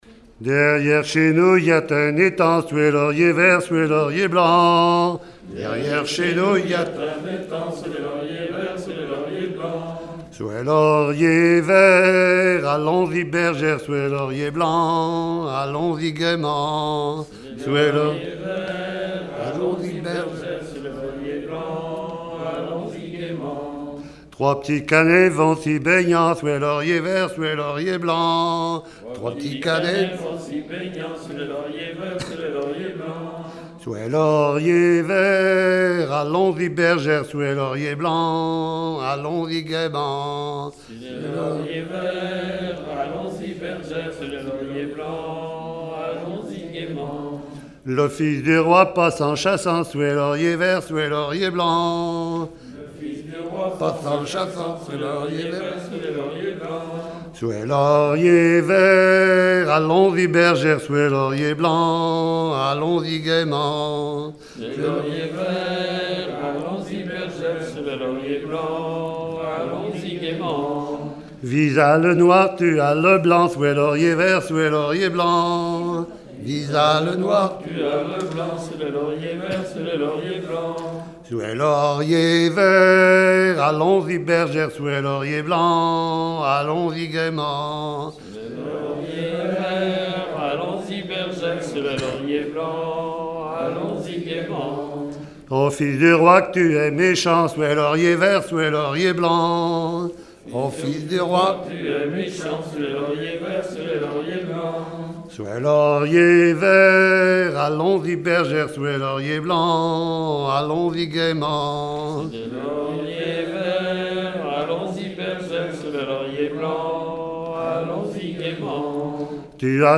gestuel : à marcher
circonstance : fiançaille, noce
Genre laisse
enregistrement de chansons
Pièce musicale inédite